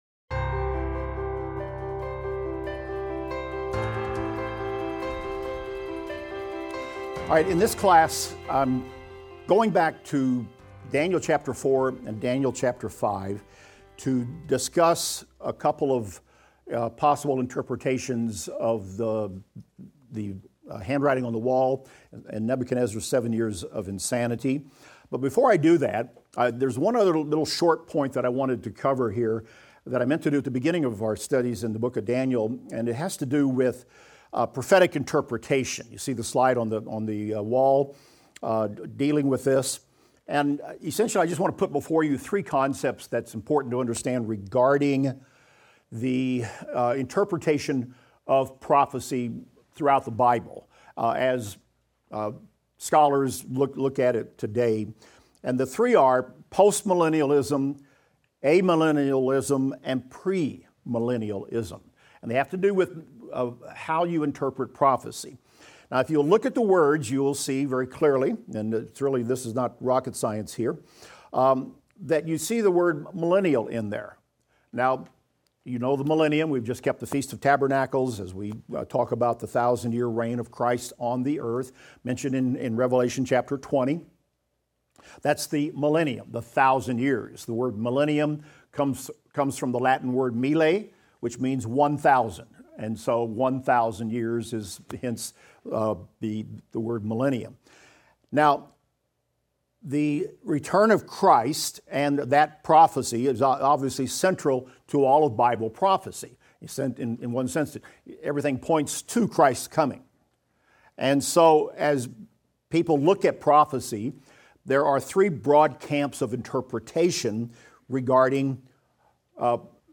Daniel - Lecture 16 - audio.mp3